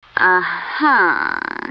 uh-huh.wav